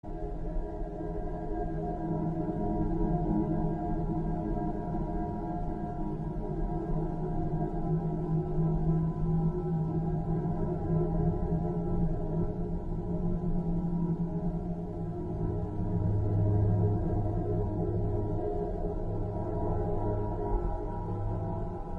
Лунные звуки космической ауры